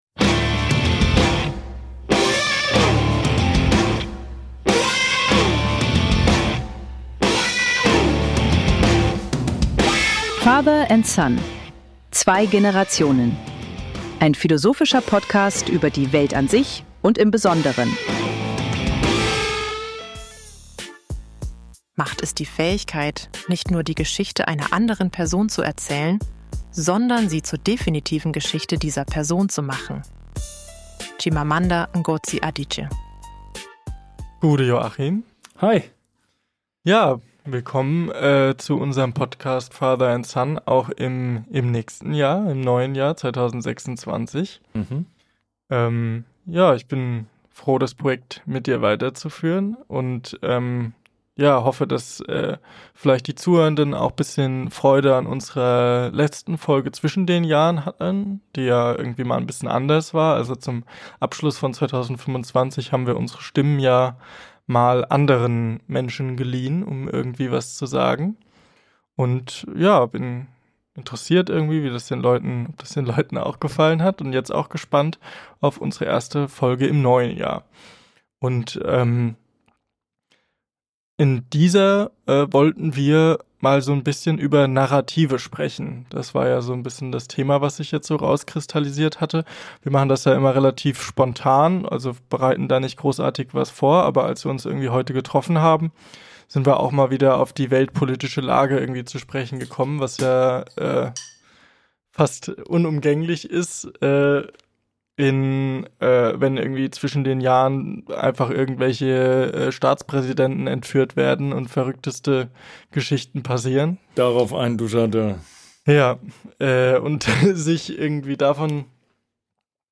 Die falschen Götter – Ein Gespräch über Narrative, Macht und warum wir ihnen folgen ~ Father&Son - 2 Generationen Podcast
Eine leidenschaftliche, wütende und zugleich analytische Folge über Ideologie, Demokratie und darüber, wie sehr unsere Wirklichkeit von Geschichten bestimmt wird, die wir selten hinterfragen.